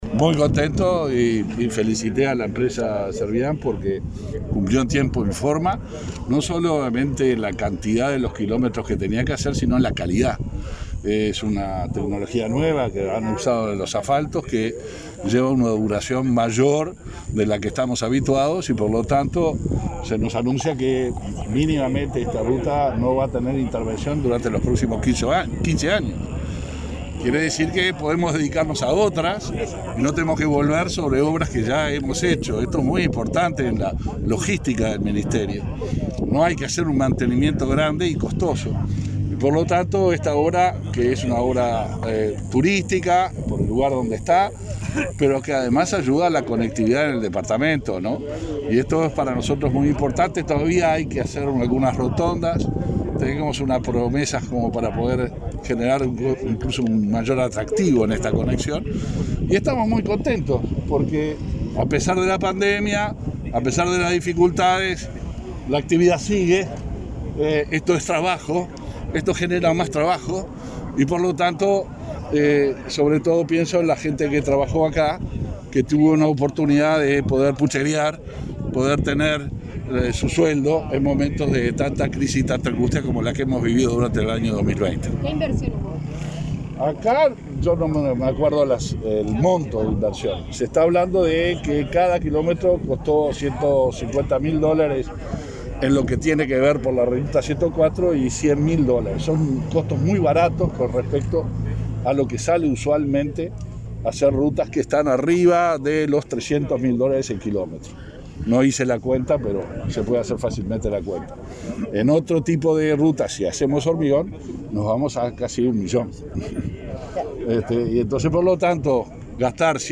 Declaraciones del ministro de Transporte y Obras Públicas, Luis Alberto Heber